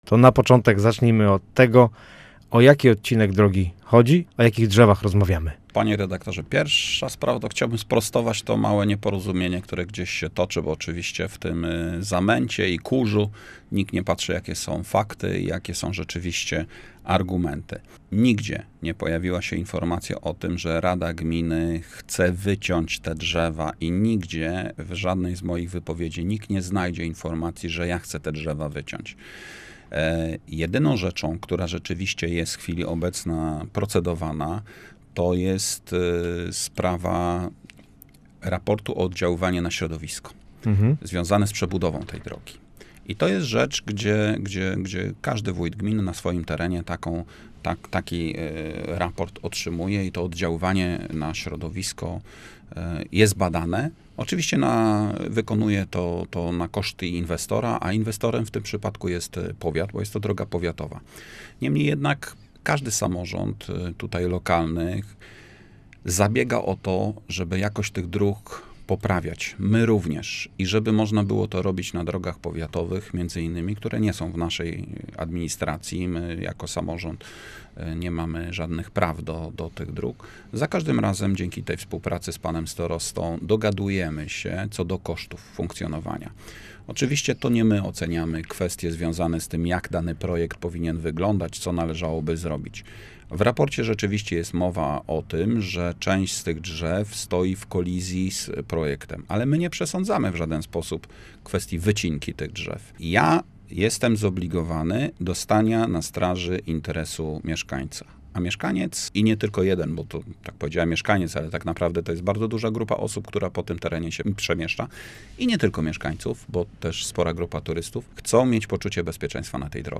Nie chcemy wycinać drzew, ale musimy zmodernizować jezdnię do standardu zapewniającego minimalne bezpieczeństwo - mówił Rafał Teterka, wójt gminy Główczyce